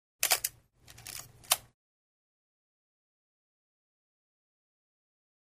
35 mm Still Camera 2; Electronic Shutter Click With Manual Film Wind.